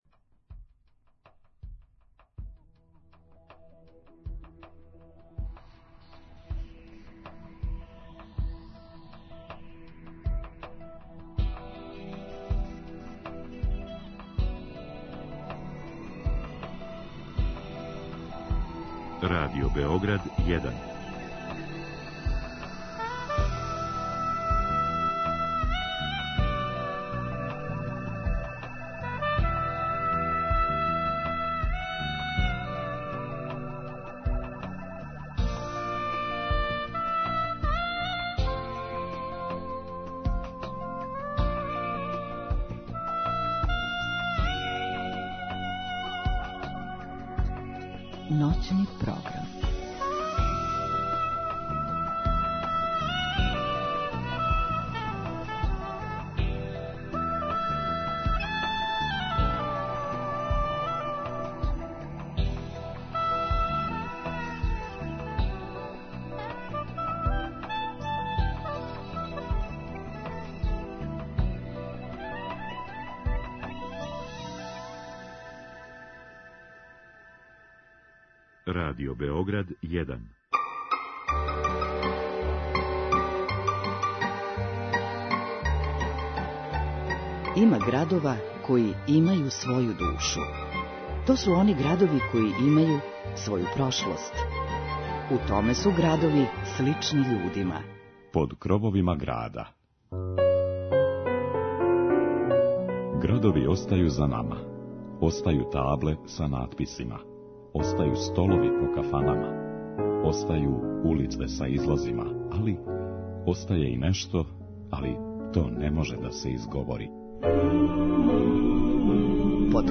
Руже миришу у том граду као ни у једном другом, а стари фијакер вози заљубљене парове кроз ноћ, уживајући уз звуке тамбураша у нашем ноћном програму.